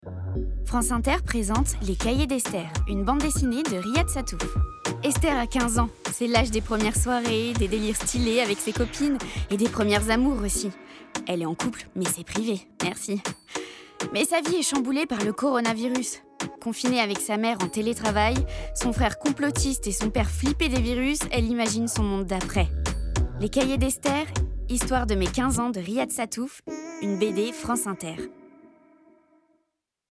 Voix off
Pub Cahier d'Esther
5 - 32 ans - Soprano